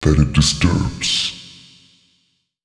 041 male.wav